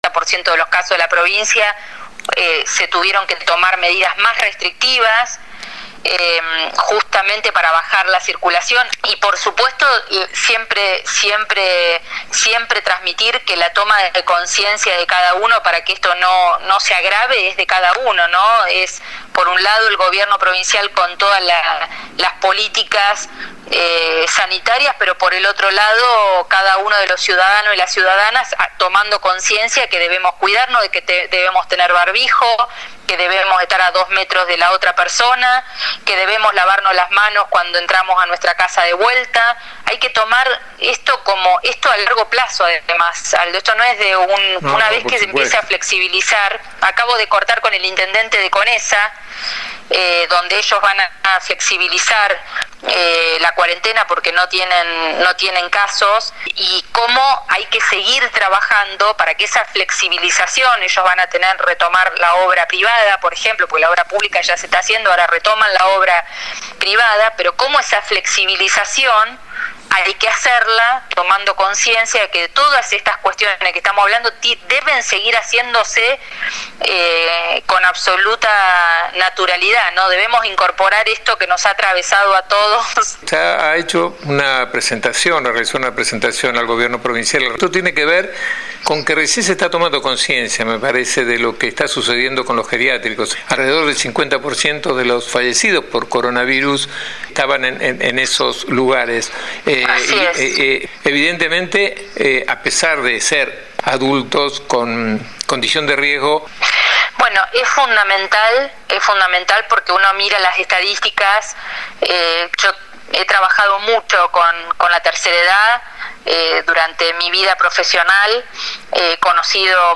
María Eugenia Martini, presidenta del bloque de legisladores del Frente de Todos habló ante los micrófonos de Antena Libre y contó sobre el plan de acción necesario en los geriátricos, también realizó un análisis de la situación sanitaria en la provincia..